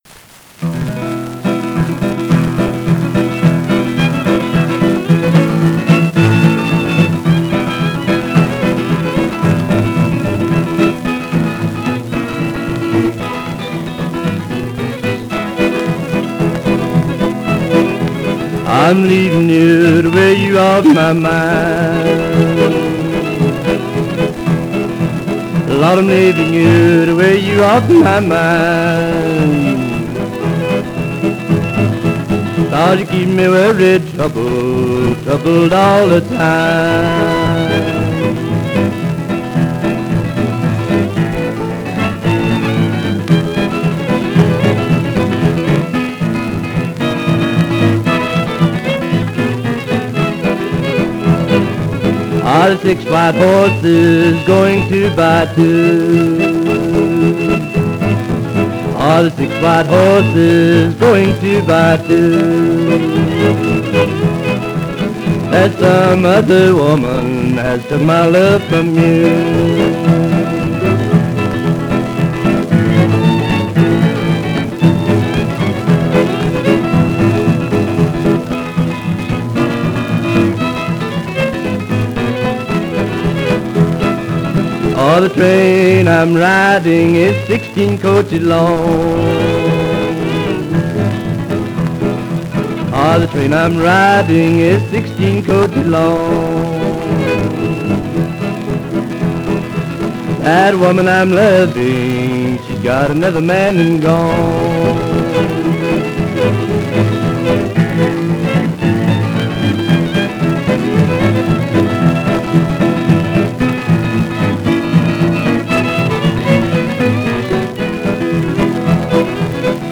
baritone vocal
twelve-bar blues